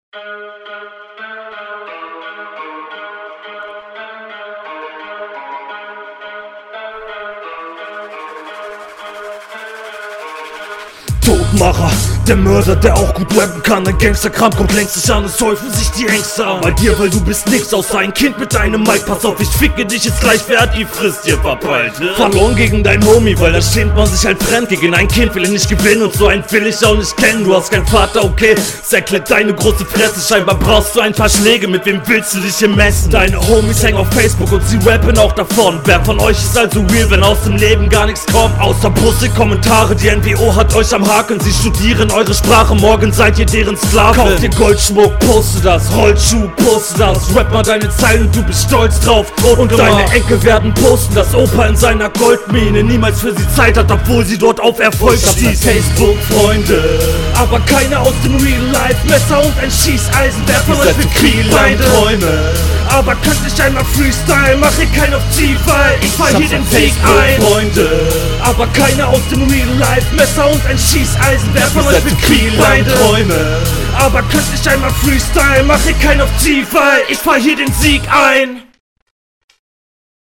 Du kommst mit Druck rein.
Sound nicht ganz so gut wie bei der HR2, aber solide.